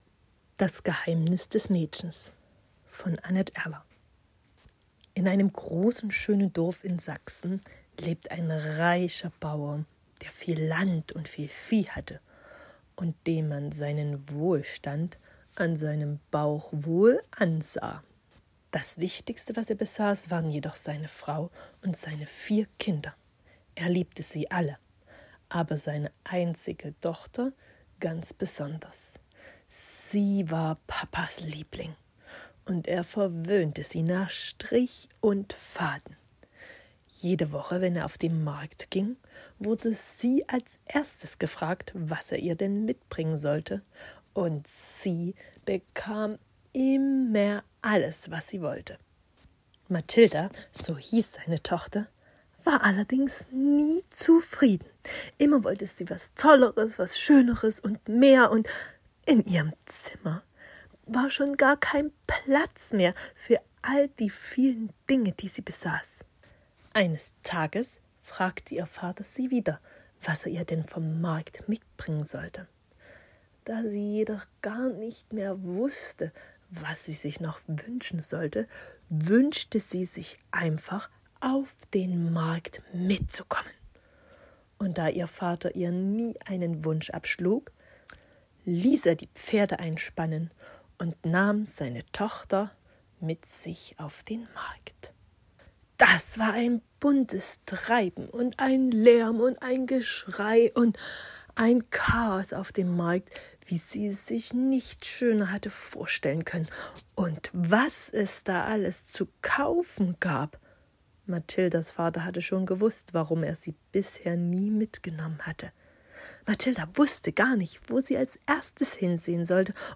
Hörbuchgeschichten